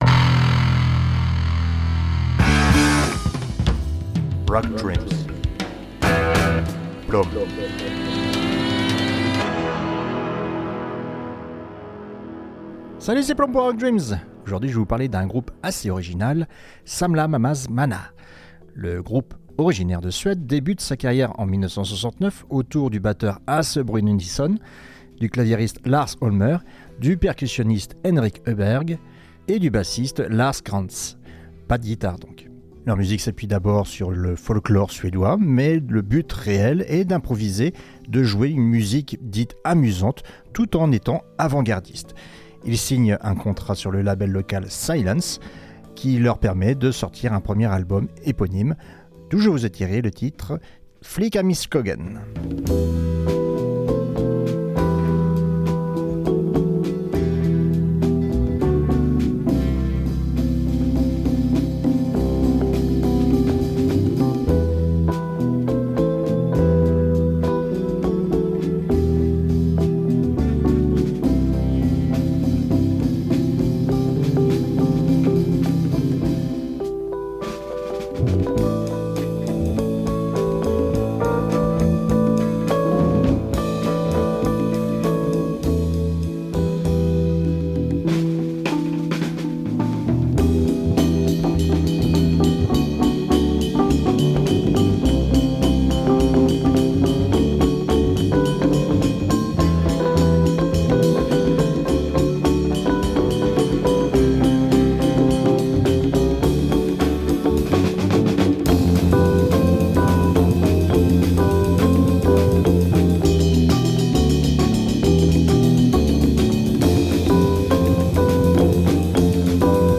Avant-Prog